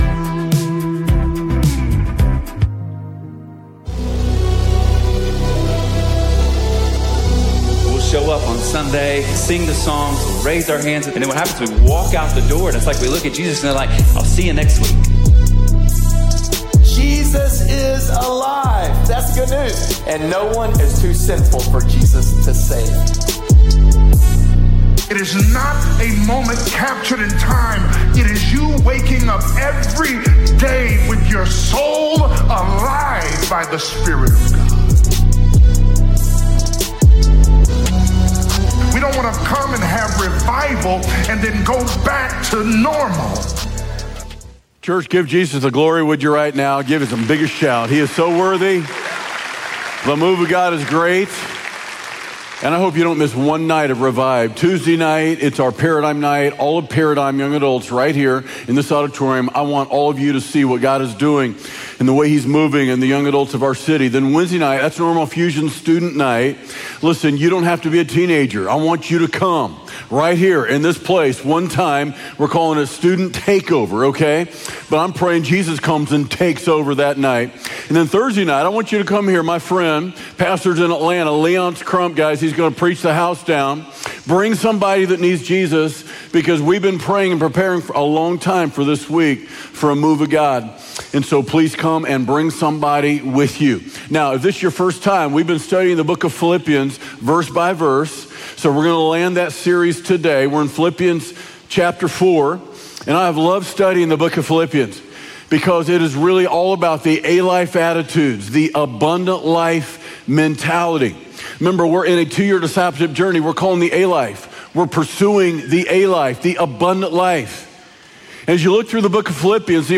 Abundant Life Sermons